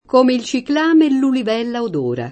ciclamino
ciclamino [ © iklam & no ] s. m.